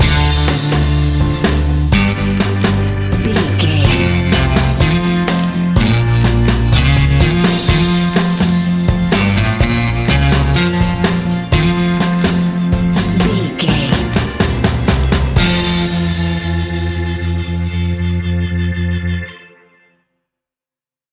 Uplifting
Ionian/Major
60s
fun
energetic
cheesy
acoustic guitars
drums
bass guitar
electric guitar
piano
electric piano
organ